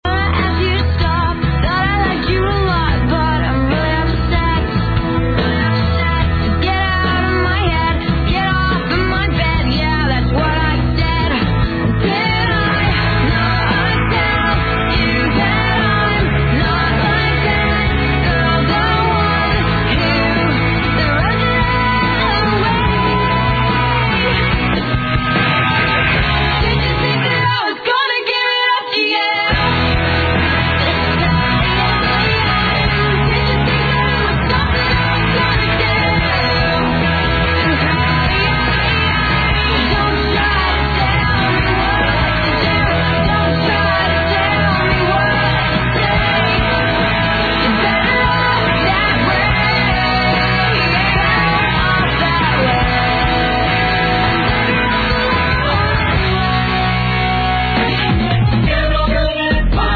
DRM stands for Digital Radio Mondiale and is an emerging standard for digital shortwave broadcast transmissions.
The DRM audio sounds pretty good to me.
Music from RNWB
To my ear they are a good reproduction of the audio quality received by Dream.